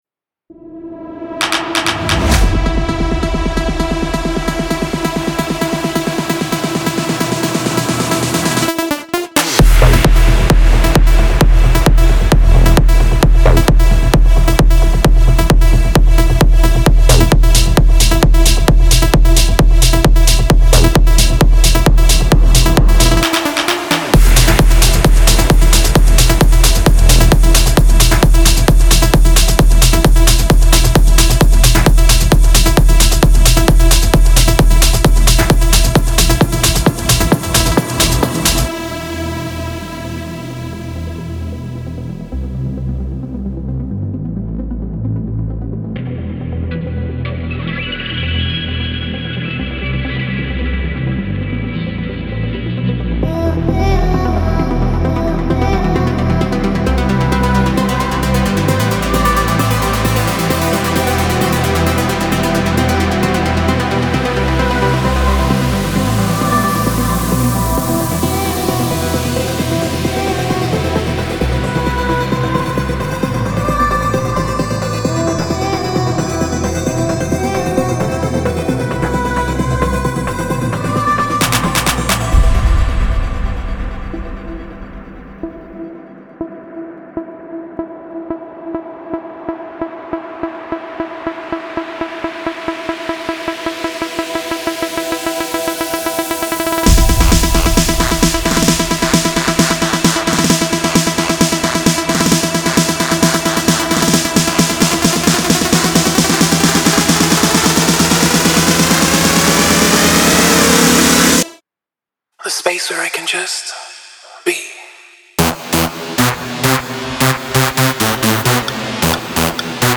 پر‌انرژی , ترنس , موسیقی بی کلام , ورزشی